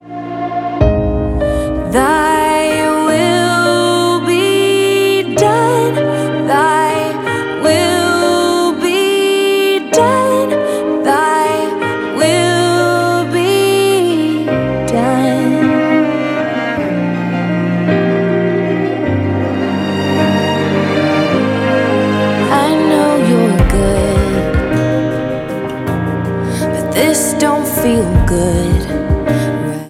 • Country
Christian country song